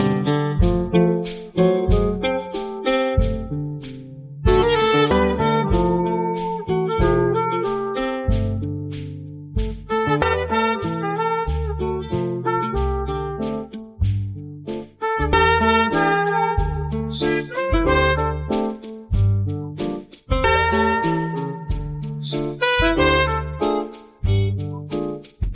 Here I gave the instruction “brass band march dirge” and requested 60 seconds of music. The result was less than 30 seconds, involved few (if any) brass instruments, and was neither a march nor a dirge.